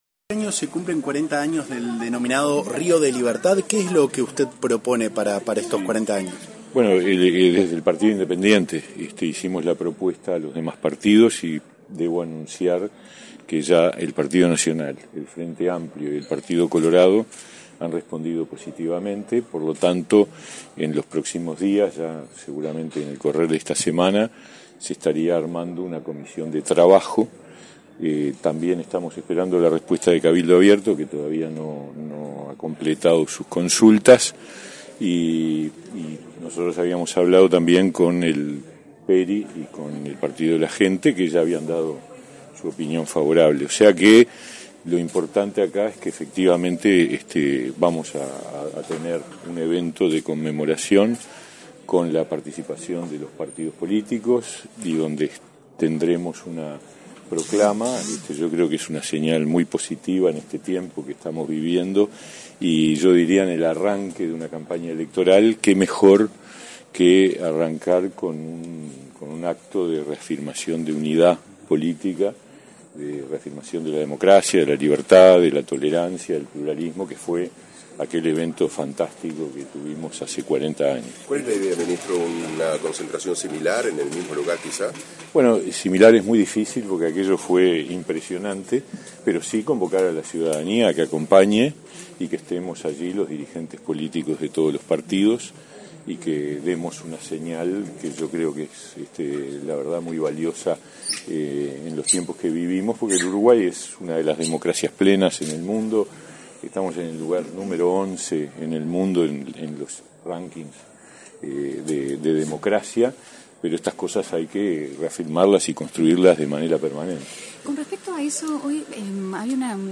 Declaraciones a la prensa del ministro de Trabajo y Seguridad Social, Pablo Mieres
El Instituto Nacional del Cooperativismo (Inacoop) realizó, este 31 de octubre, un acto por el 15.° aniversario de la promulgación de la Ley General de Cooperativas n.° 18.407. En la oportunidad, el ministro de Trabajo y Seguridad Social, Pablo Mieres, realizó declaraciones a la prensa.